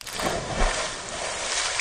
ship-sinking-rebalanced.wav